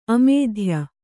♪ amēdhya